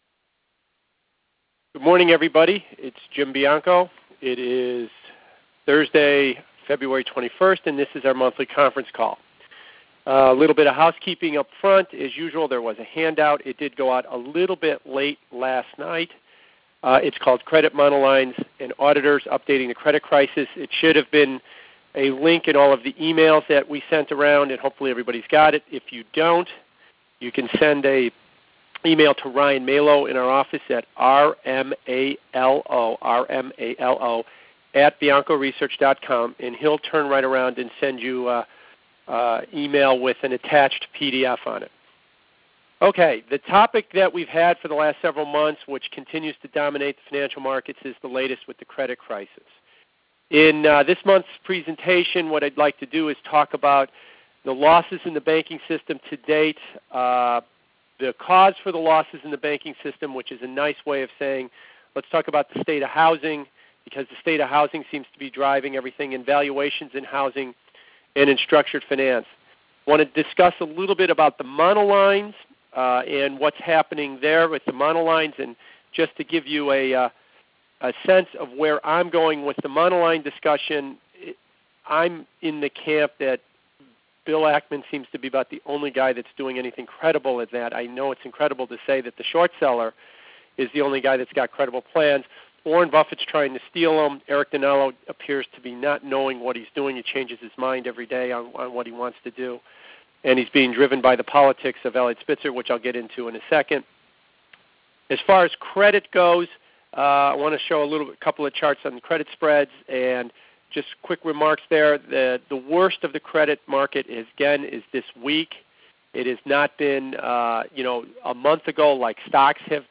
Credit, Monolines And Auditors: Updating The Credit Crisis Audio Link of Conference Call February Conference Call Presentation Package.